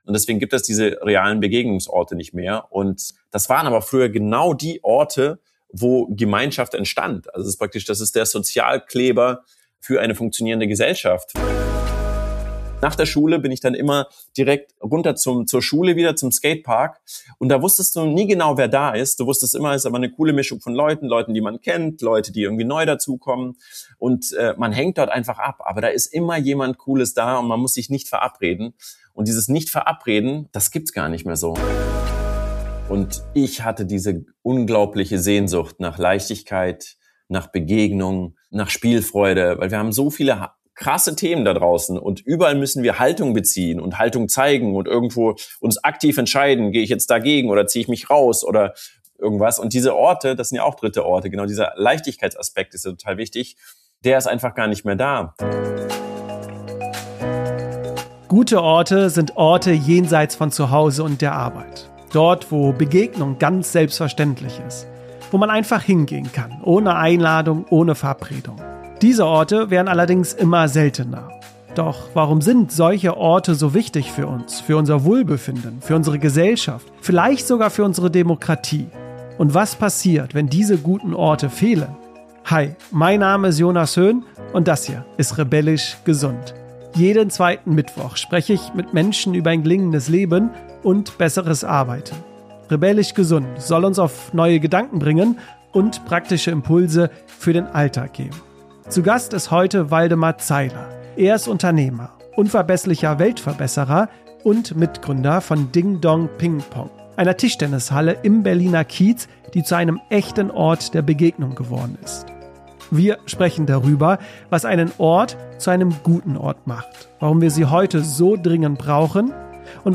Ein Gespräch über gute Orte, soziale Nähe und darüber, was wir gerade verlieren – und was wir wiedergewinnen können.